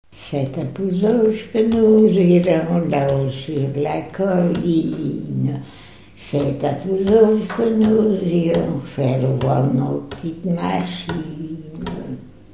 Mémoires et Patrimoines vivants - RaddO est une base de données d'archives iconographiques et sonores.
refrain de conscrits
Chants brefs - Conscription
Pièce musicale inédite